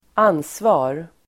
Uttal: [²'an:sva:r]